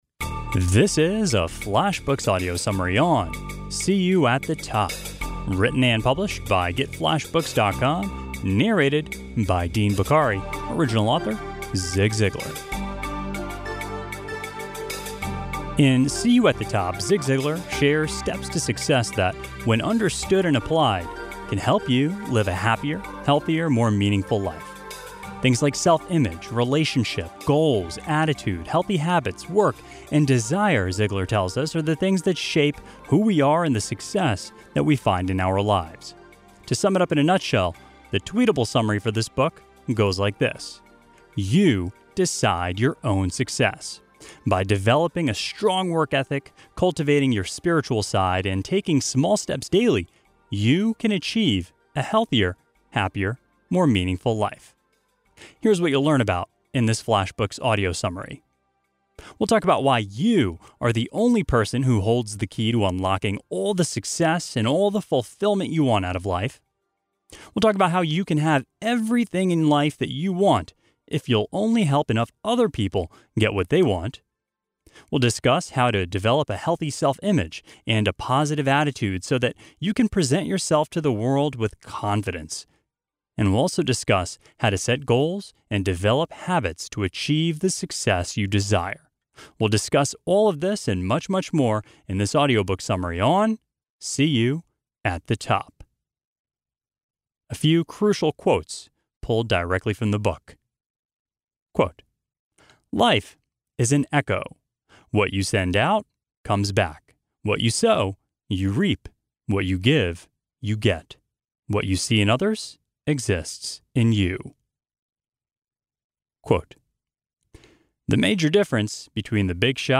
SeeYouAtTheTopbyZigZiglar-AudiobookSummaryMP3.mp3